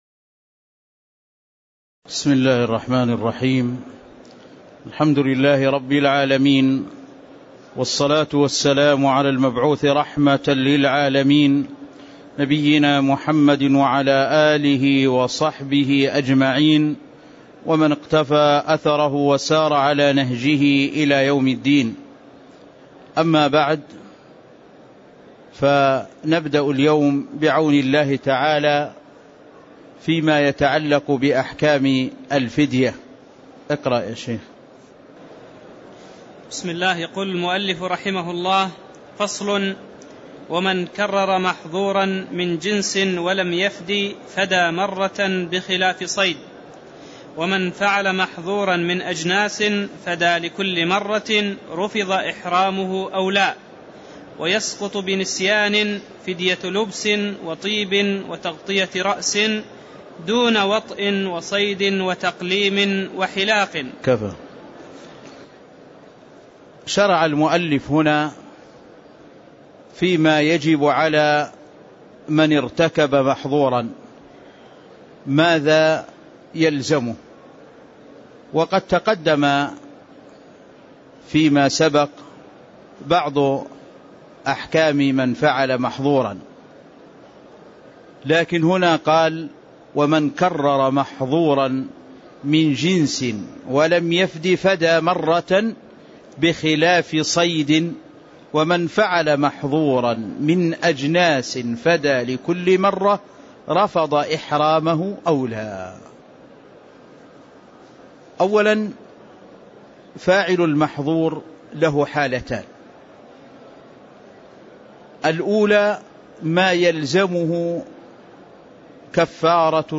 تاريخ النشر ٣ ذو الحجة ١٤٣٥ هـ المكان: المسجد النبوي الشيخ